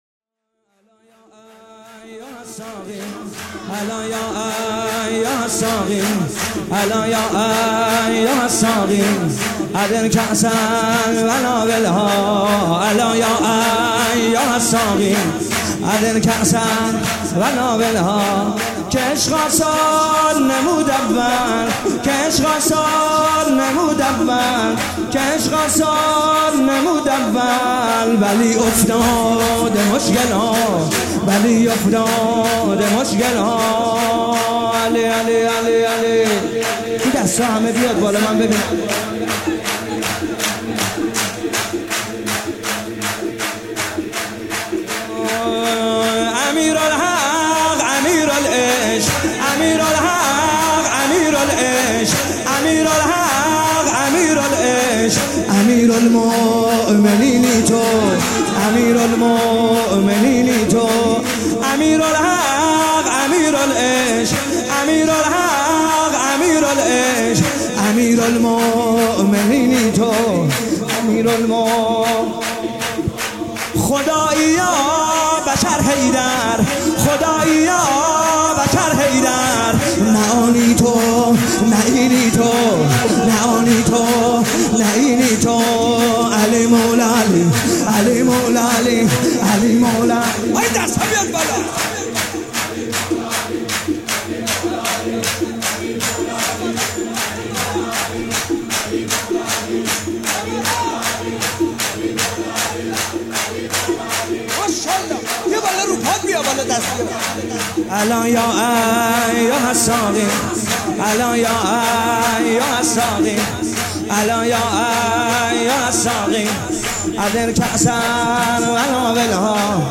مولودی امام حسن عسکری شاد